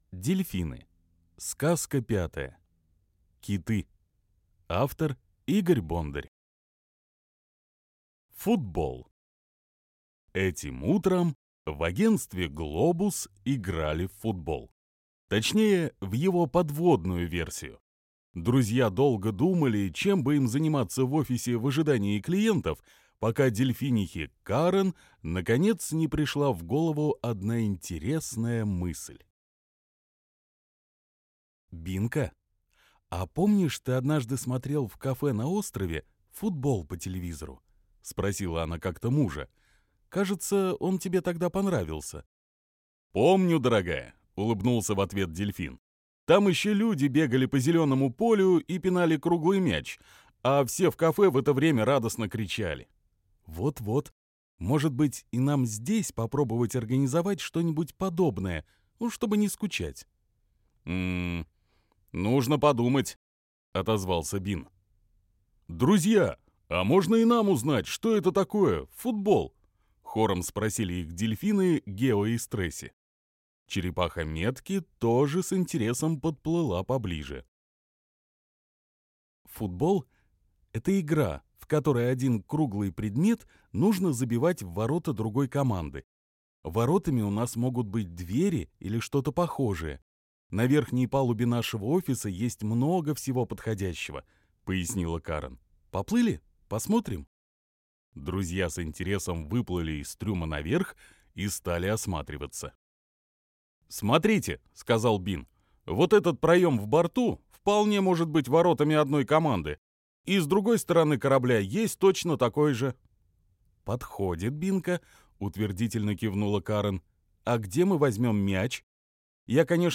Дельфины. Киты - аудиосказка Бондаря - слушать онлайн